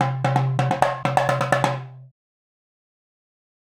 Indian Drum 01.wav